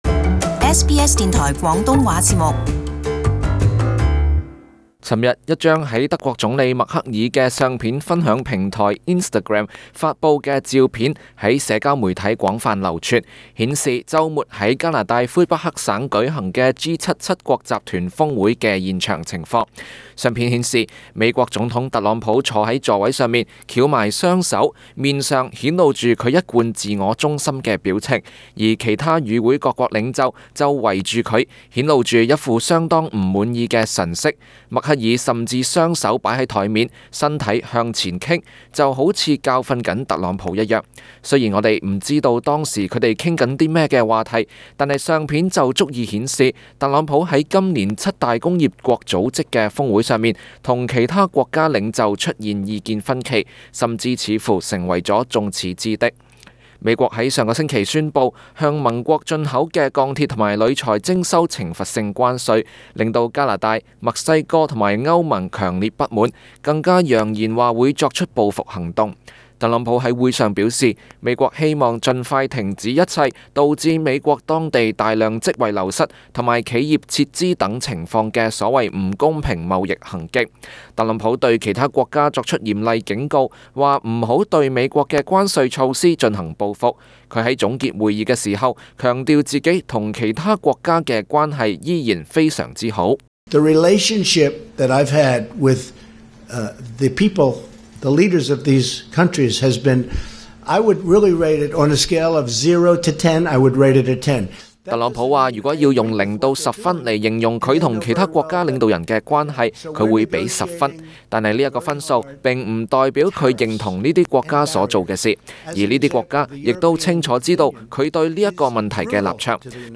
【時事報導】特朗普意氣用事拒簽G7閉幕聲明？